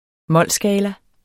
Udtale [ ˈmʌlˀ- ]